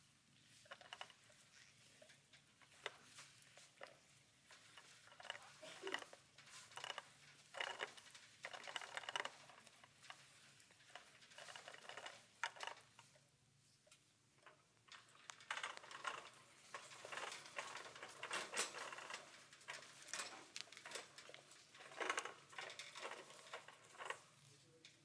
Carro de la compra | SÓNEC | Sonoteca de Música Experimental y Arte Sonoro
Acción de circular por el supermercado
Materia: Sonidos de la cotidianidad
Carrocompra.mp3